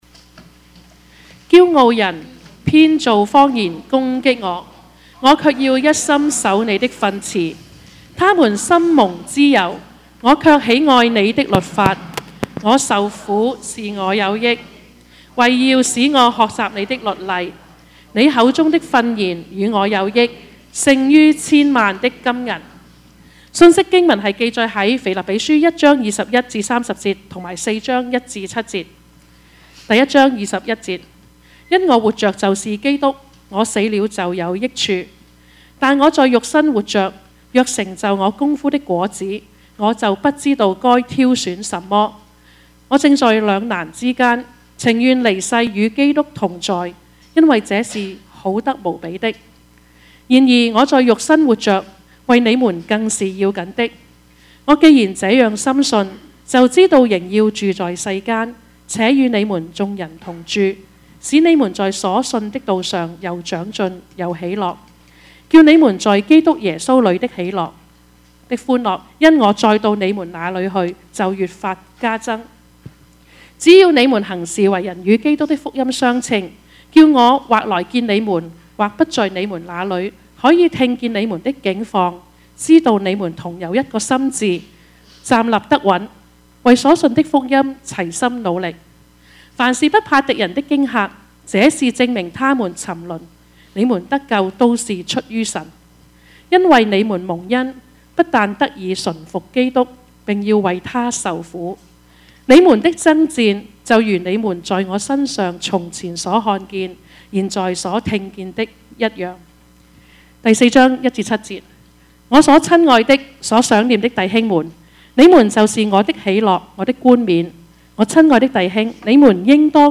Topics: 主日證道 « 被擄的得釋放 大與小、寬與窄、前與後 »